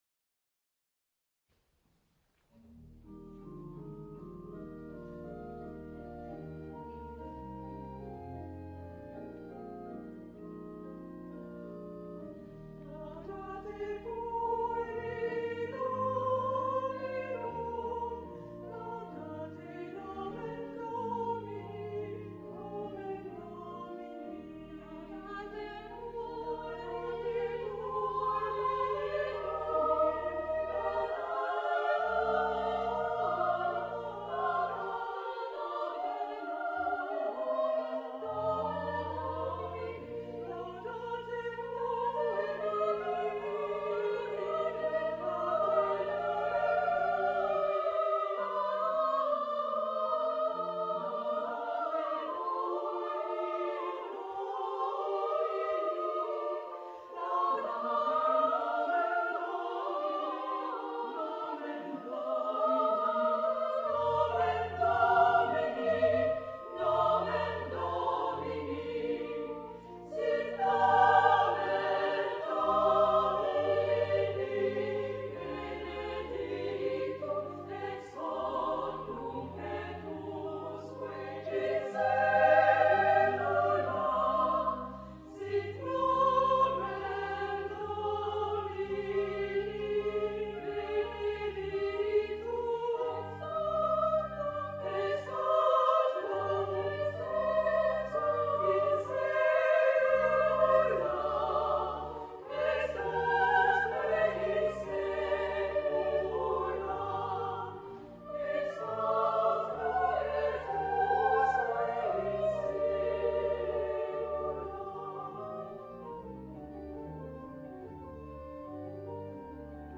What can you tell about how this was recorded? Programme of the March 11th concert 2001